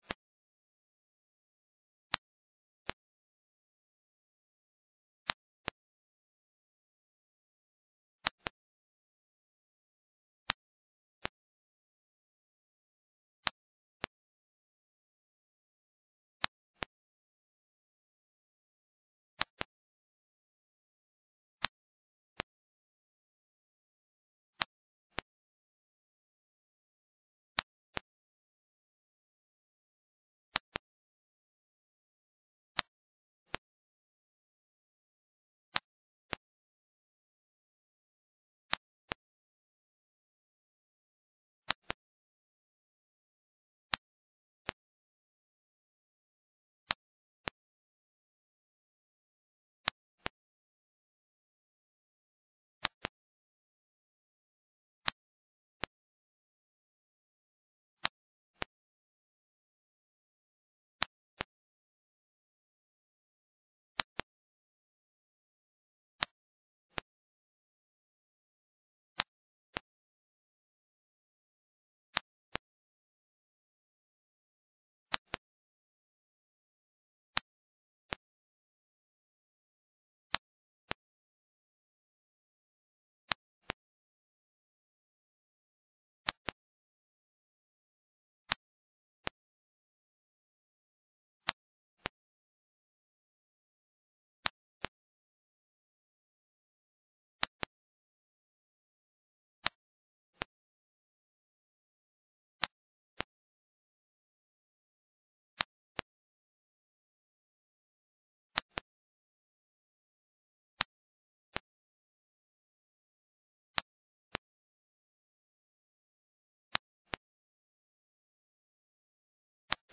CEF la Borne, Culte du Dimanche, La Combinaison divine dans le mariage